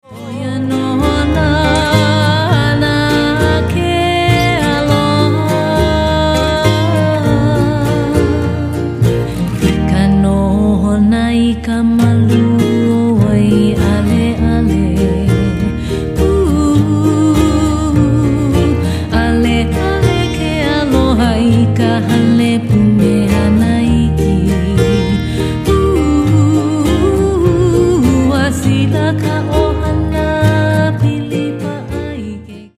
Genre: Traditional Hawaiian.
continues to blossom with her seductive vocal work.